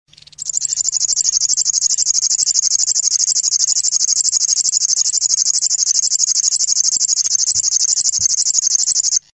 berkitucsokmadar_locustellafluviatilis00.09.wav